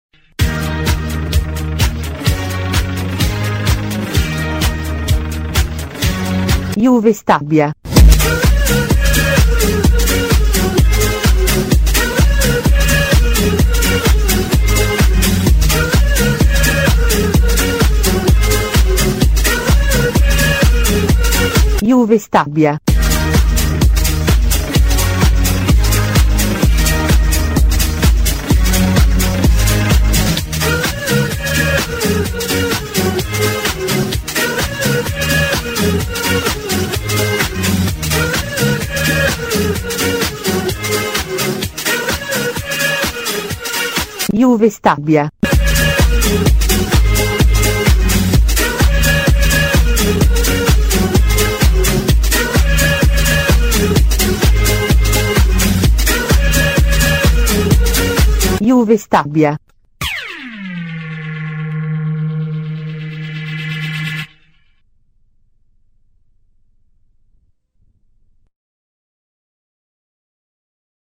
La Suoneria dello Stadio